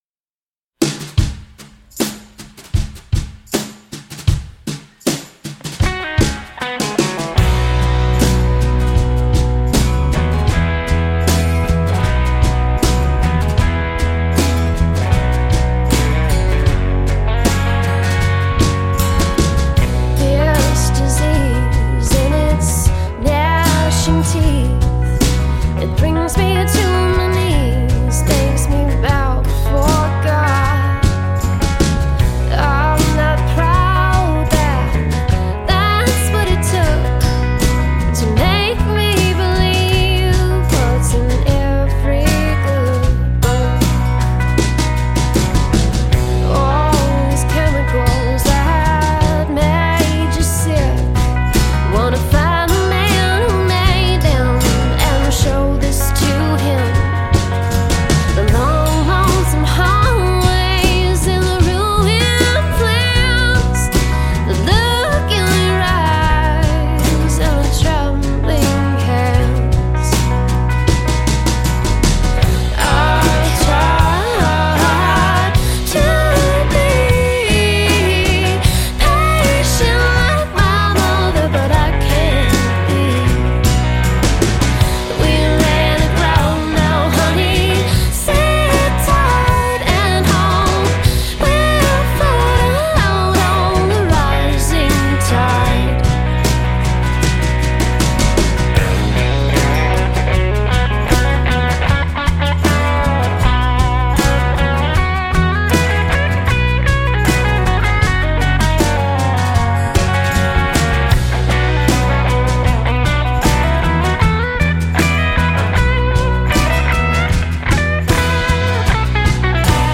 indie folk
poignant and heartfelt song